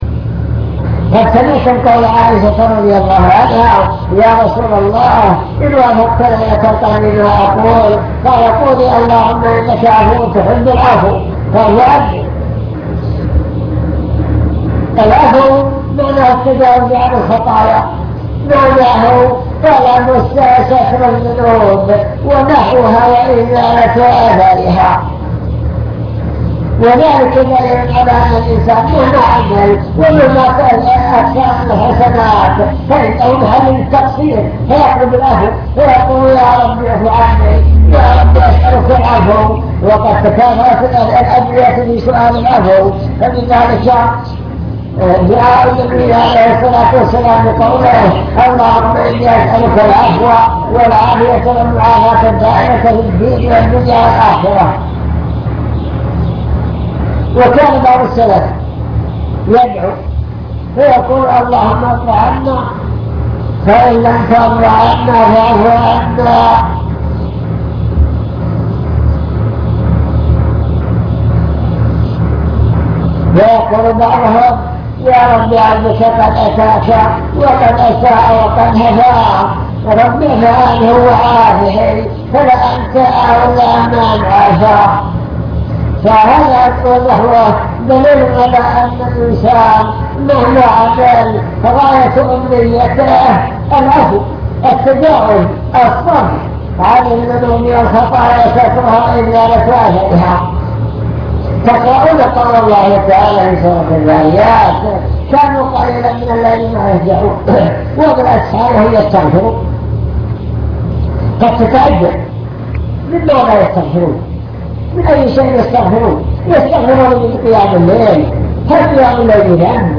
المكتبة الصوتية  تسجيلات - محاضرات ودروس  مجموعة محاضرات ودروس عن رمضان